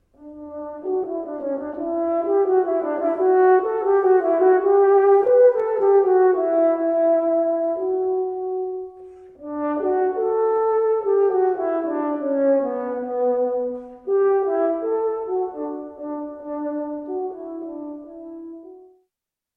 TROMPA
Como ten moita lonxitude de tubo, aínda que estea enroscado, produce un son moi profundo e intenso, empregado habitualmente para as bandas sonoras do cine.
trompa.mp3